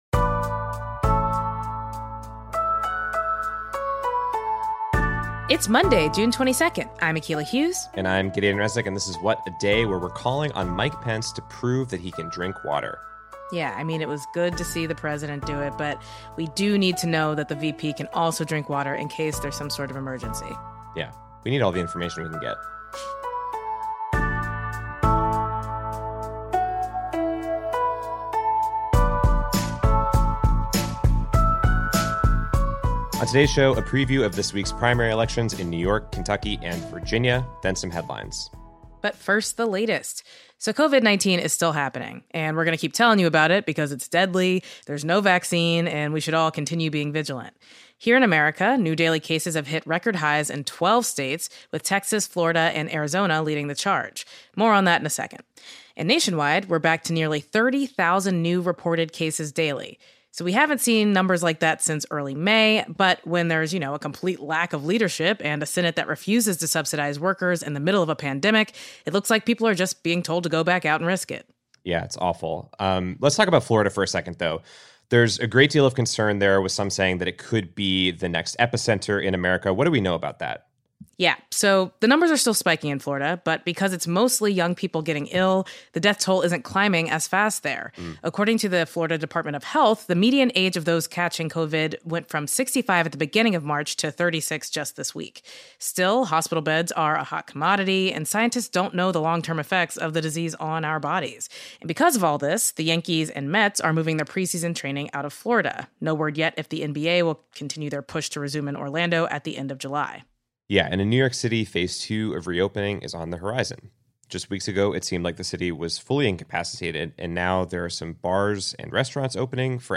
New York, Virginia, and Kentucky have primaries on Tuesday. We speak with two progressive candidates for congress: Jamaal Bowman, who’s running against incumbent Rep. Eliot Engel for New York’s 16th congressional district, and Charles Booker, who’s running against Amy McGrath, for senate in Kentucky.